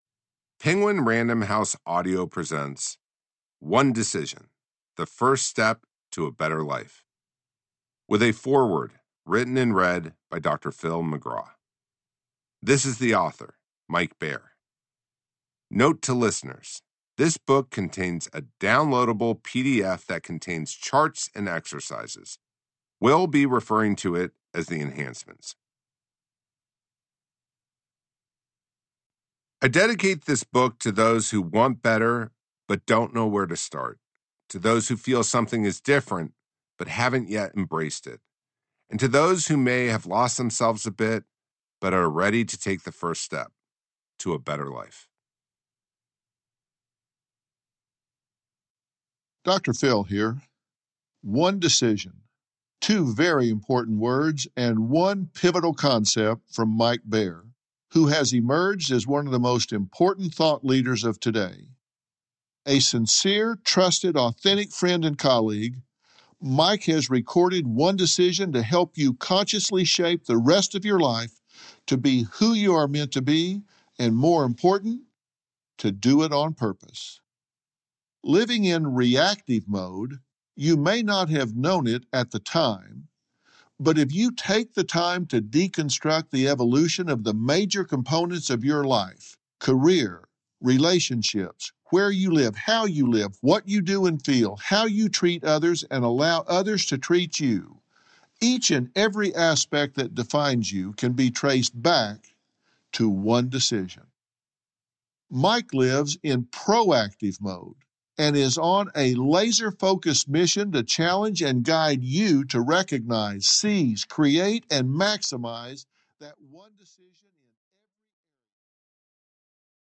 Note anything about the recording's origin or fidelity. digital digital digital stereo audio file Notes: Electronic audio file